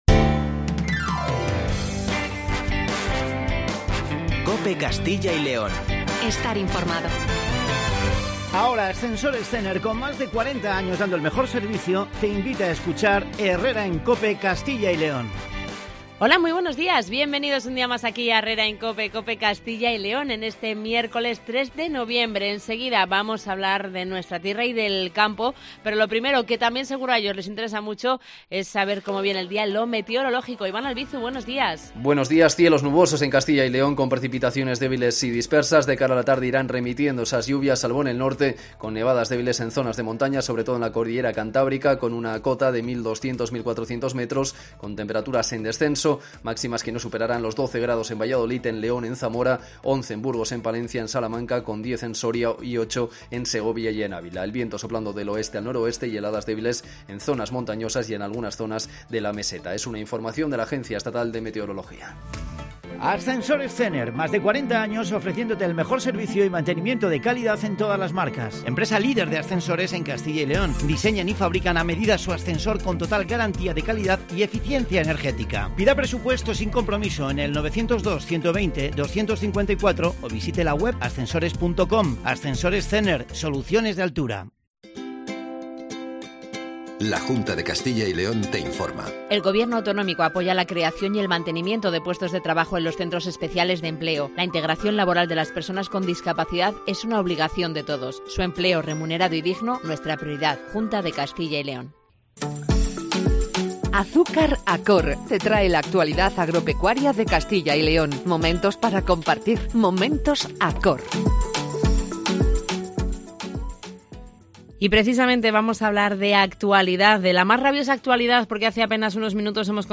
AUDIO: En el tiempo semanal para la agricultura de la mano de ACOR entrevistamos al consejero Jesús Julio Carnero.